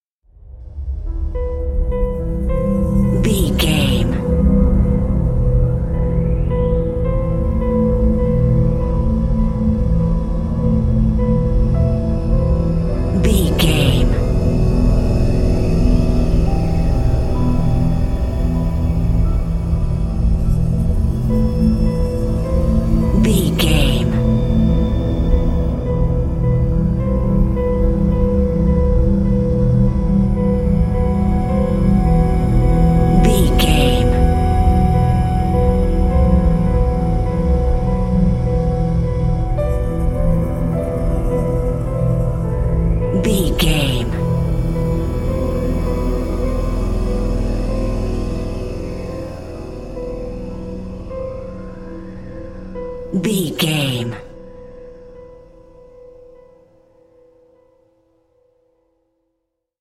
Thriller
Aeolian/Minor
Slow
piano
synthesiser
electric piano
ominous
suspense
haunting
creepy